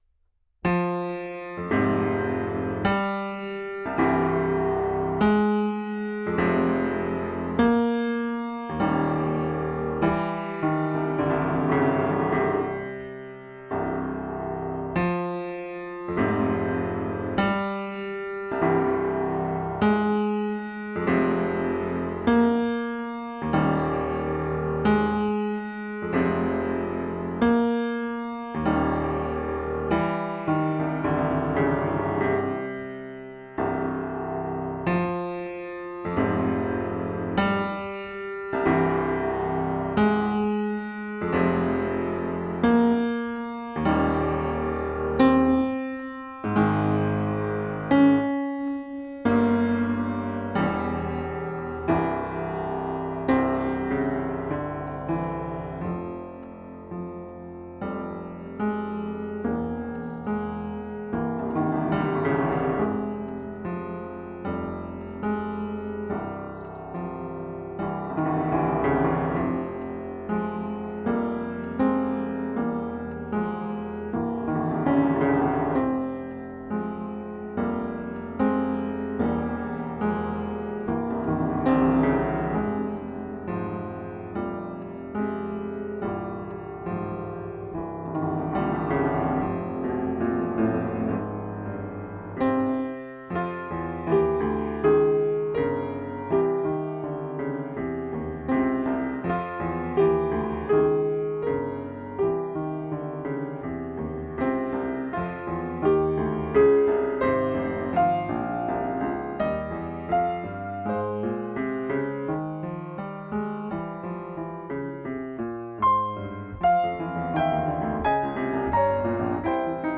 Version Piano 4 mains: